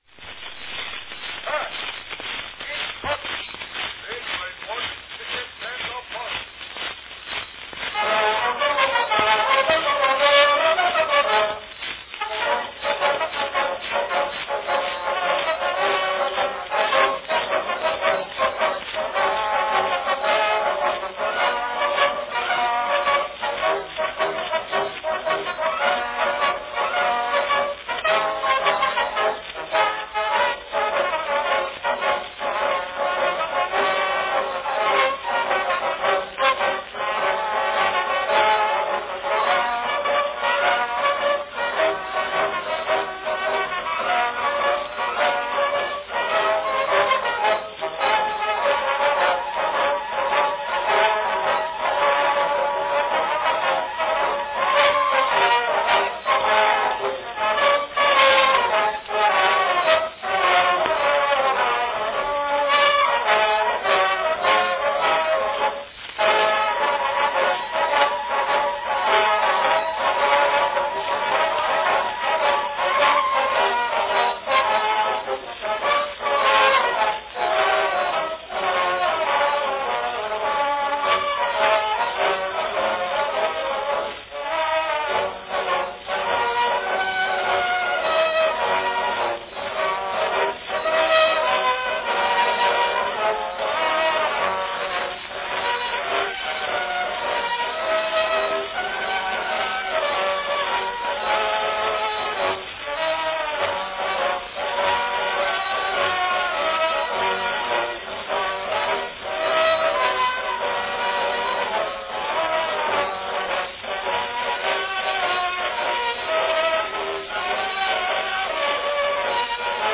From 1896, an early recording of John Philip Sousa's famous King Cotton March, played by Baldwin's Cadet Band of Boston.
Category Band
Performed by Baldwin's Cadet Band
Announcement "March, King Cotton, played by Baldwin's Cadet Band of Boston."